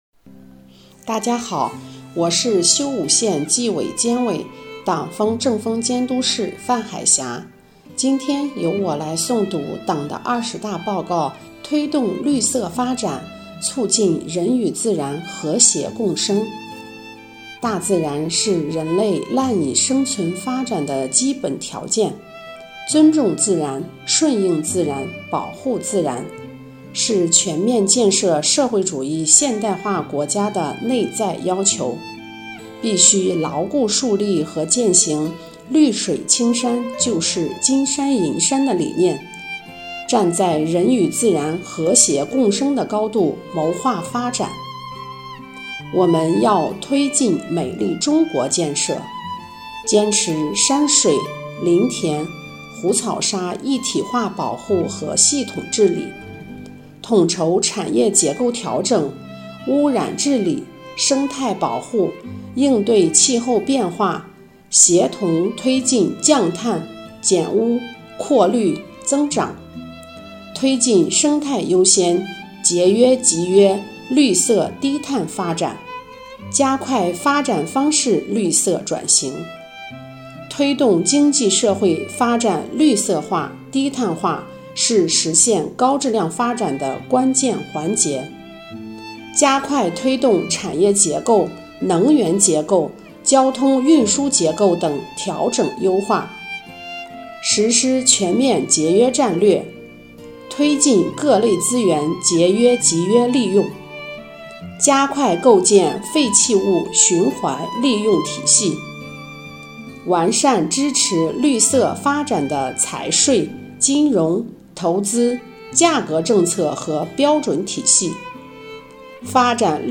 为深入学习宣传贯彻党的二十大精神，切实把全市纪检监察干部的思想统一到党的二十大精神上来，把力量凝聚到党的二十大确定的各项任务上来，即日起，“清风焦作”公众号推出“共学二十大•接力读原文”活动，摘选各单位宣讲员诵读党的二十大报告原文，敬请关注！